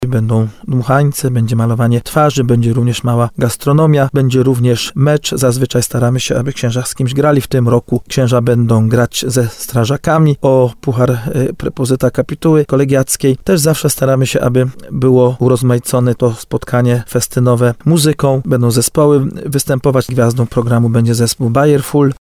3ksiadz.mp3